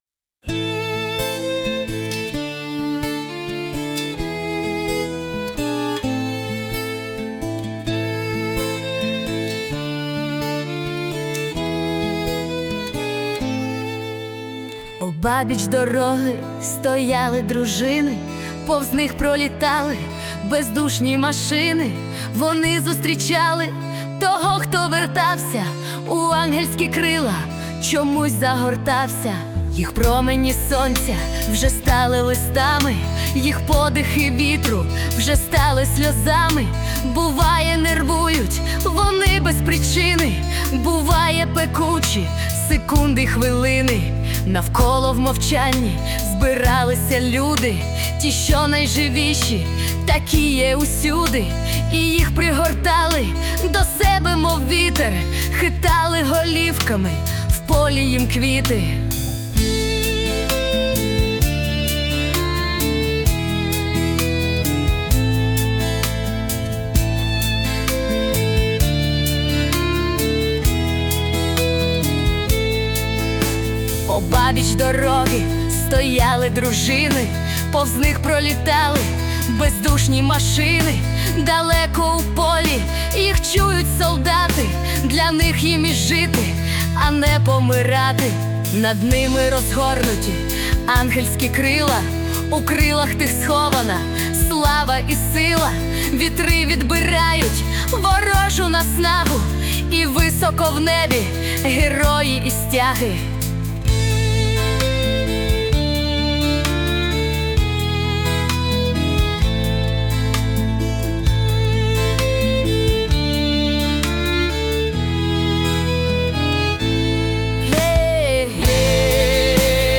музичний супровід з допомогою ШІ
СТИЛЬОВІ ЖАНРИ: Ліричний